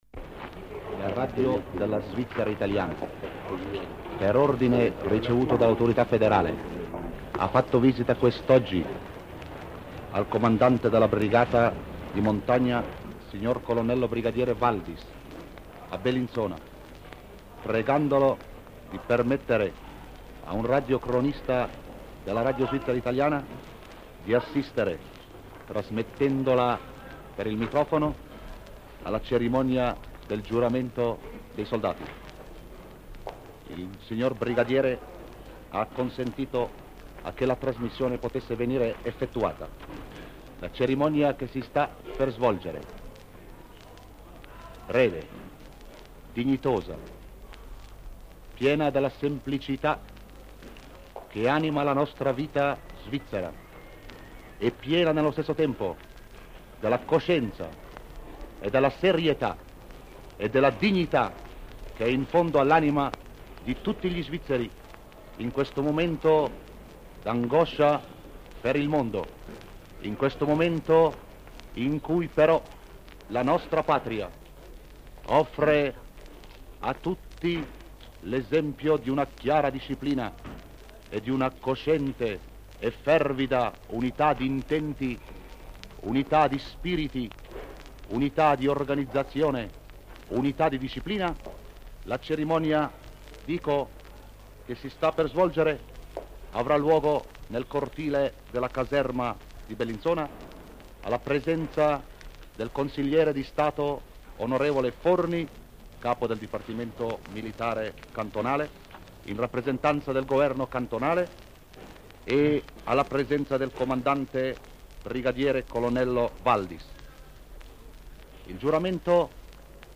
Cronaca della cerimonia del giuramento dei soldati alla caserma di Bellinzona diffusa dalla Radio della Svizzera italiana il 2 novembre 1939.